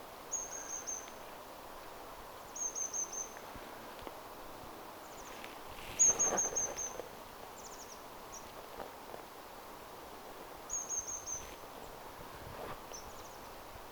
pyrstötiainen
Yksittäisen linnun selkeitä ääniä.
pyrstotiainen.mp3